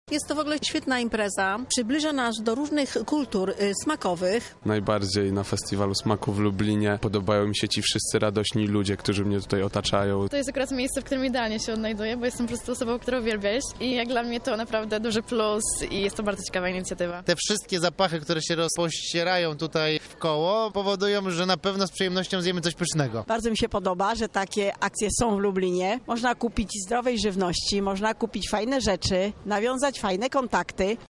Zapytaliśmy Lublinian o ich zdanie na temat imprezy: